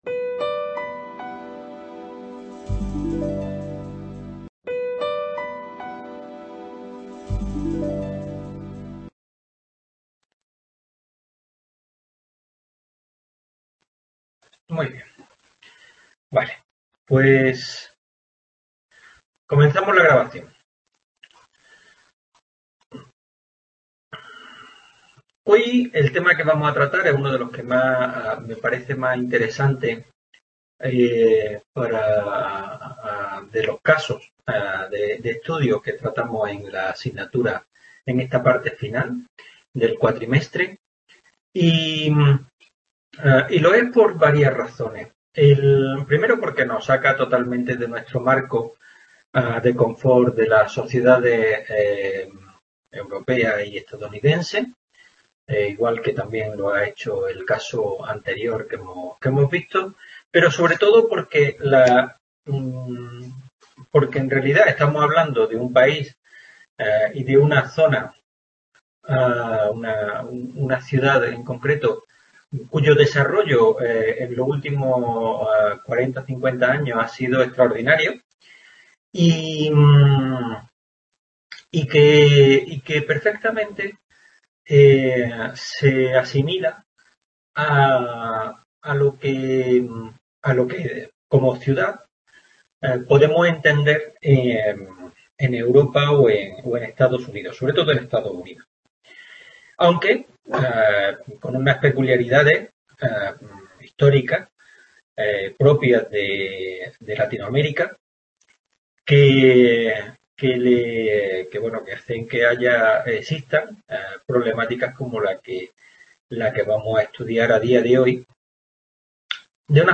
Este vídeo es un montaje realizado con materiales audiovisuales de otros años.
Por ello, la coherencia del vídeo a lo largo de la exposición es relativamente baja.